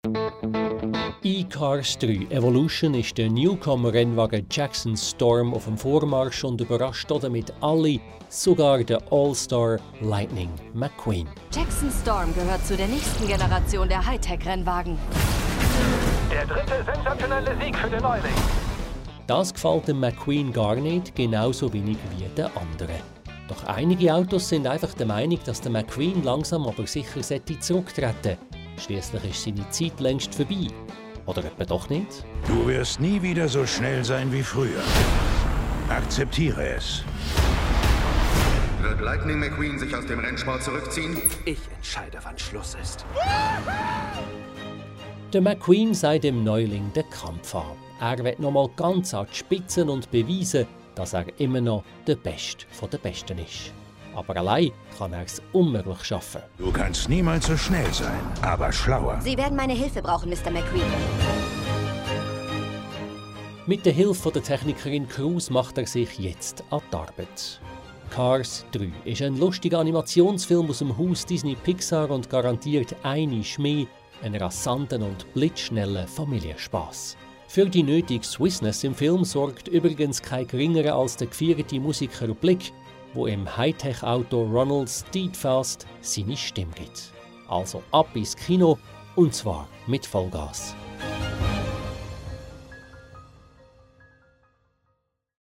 Cars-3-Featurette-01-Sendefertige-Reportage.mp3